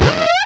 Cri de Moufouette dans Pokémon Diamant et Perle.